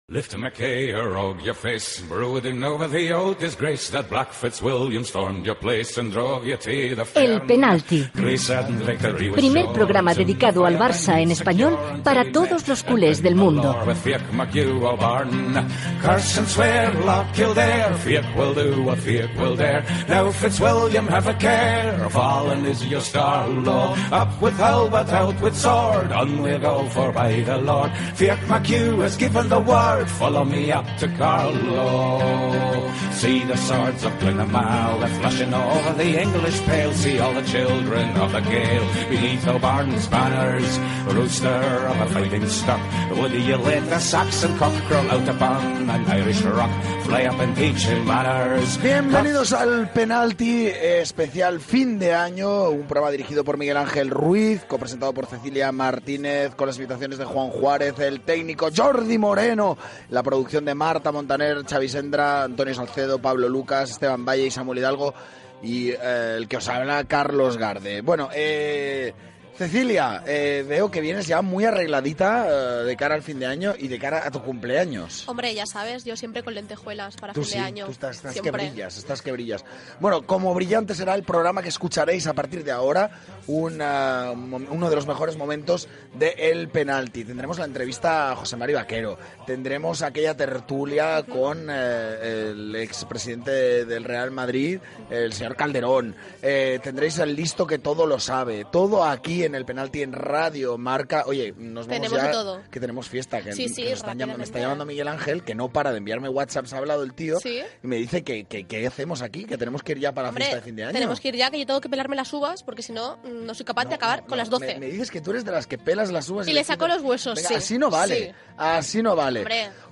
Identificació del programa, salutació, equip, avenç de continguts, indicatiu, imitació de l'entrenador José Mourinho, publicitat, indicatiu, entrevista a l'exjugador de futbol José Mari Bakero
Esportiu